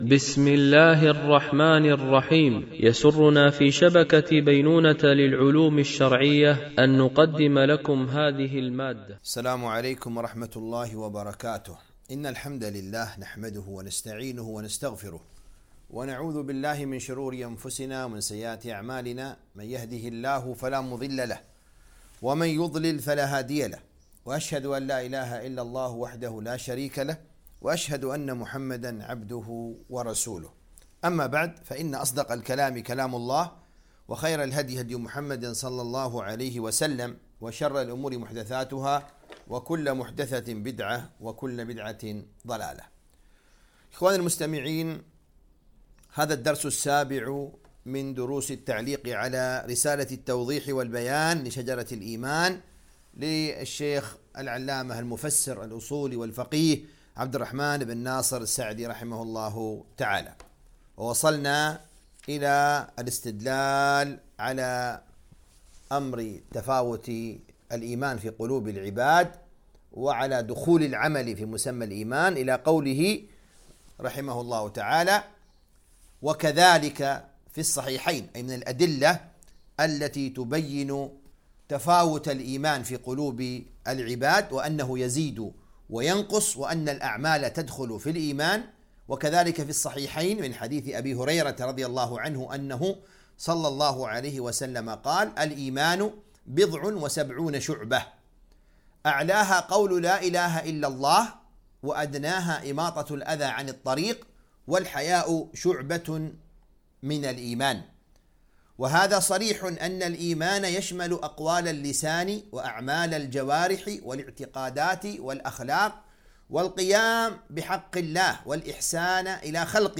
التعليق على كتاب التوضيح والبيان لشجرة الإيمان ـ الدرس 07